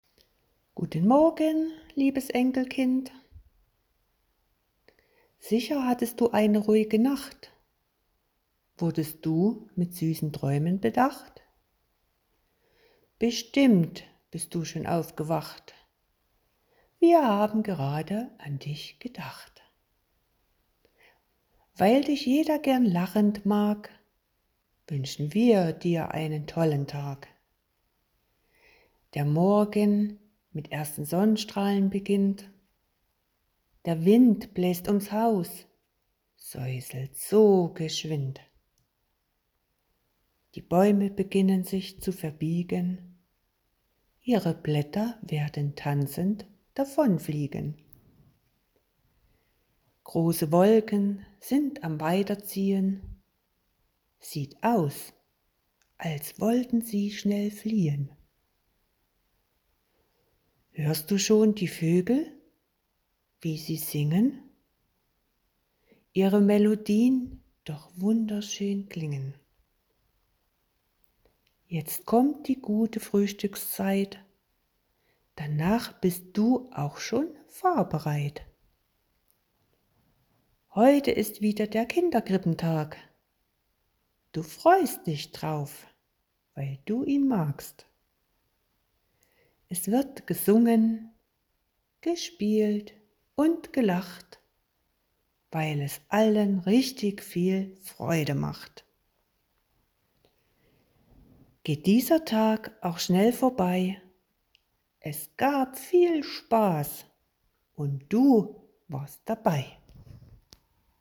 Vertontes Gedicht